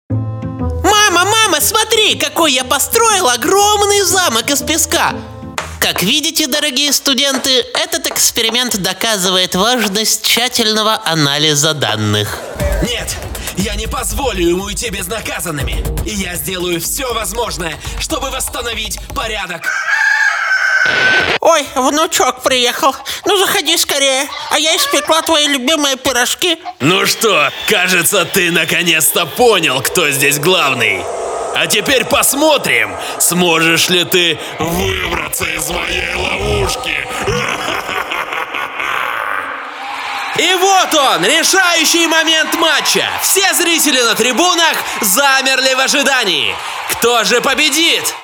Конденсаторный микрофон Scarlett CM25 MkIII; Аудио-интерфейс: Lexicon Omega, Scarlett Focusrite Solo, Scarlett Focusrite 4i4 3Gen
Демо-запись №3 Скачать